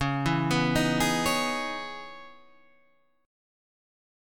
C#m9 chord {9 7 9 8 9 7} chord